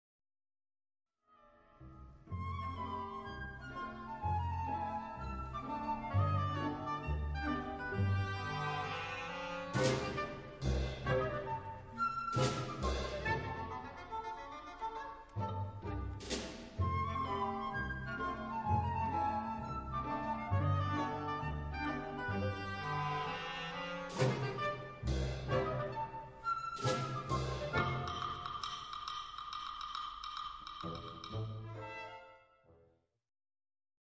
April 2002 Concert
Hear the woodwind sparkle in Facade, and the whole orchestra rise to the challenge in the Spitfire Prelude & Fugue.